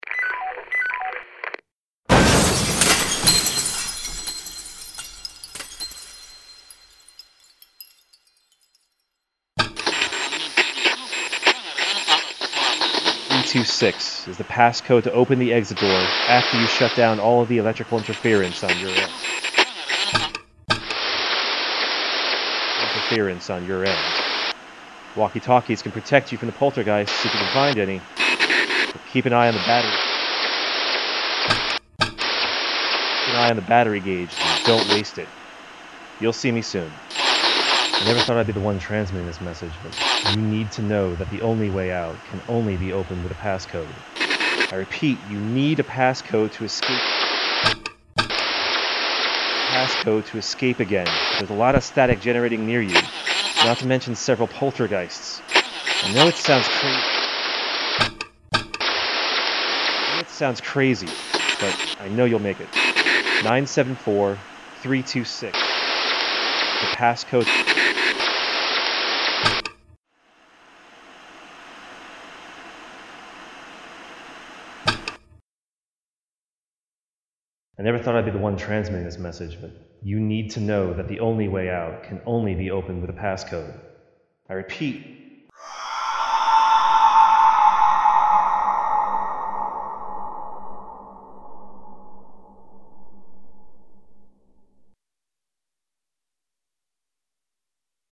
I wrote and performed the audio narrative of the game. The writing was designed to loop the narrative and allow each segment to still make sense on its own for each step in the game.